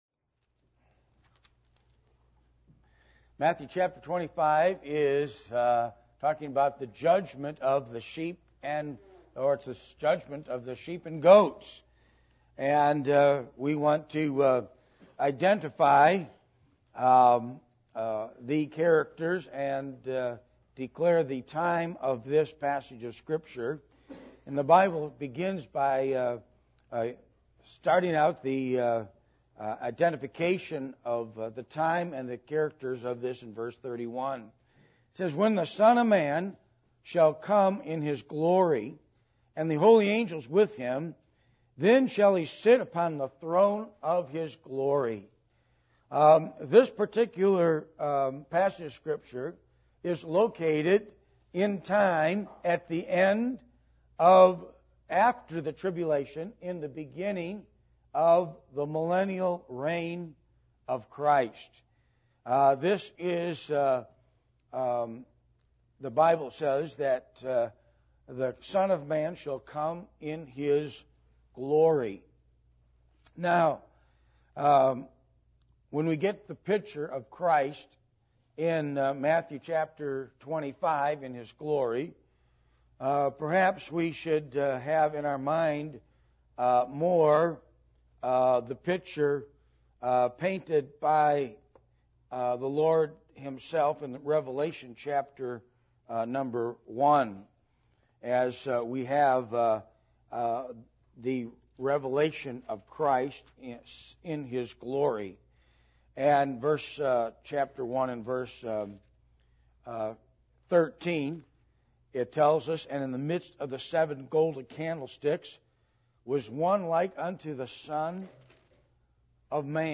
Matthew 25:31-46 Service Type: Sunday Morning %todo_render% « Biblical Principles Of Finances